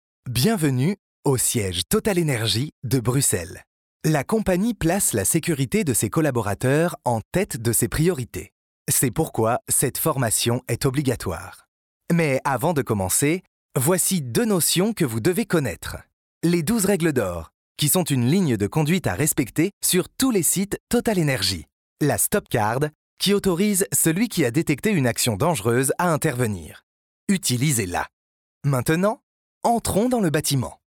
Natural, Versatile, Friendly
Corporate